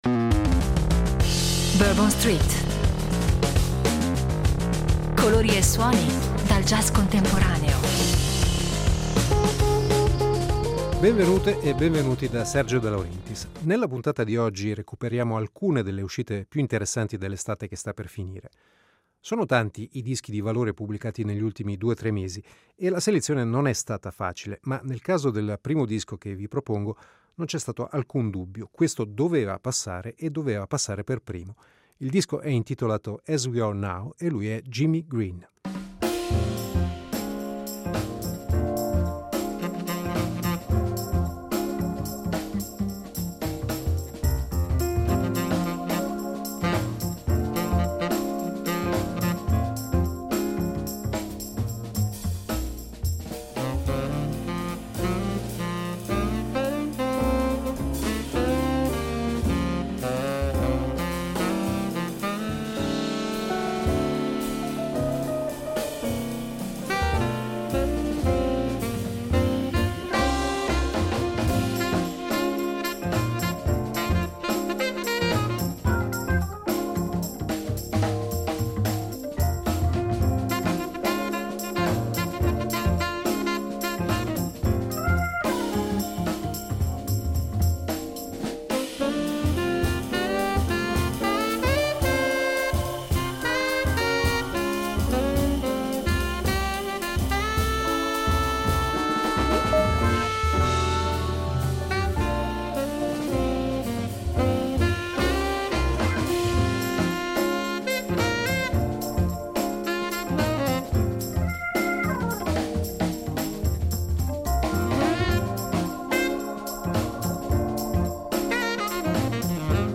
sassofonista statunitense
piano jazz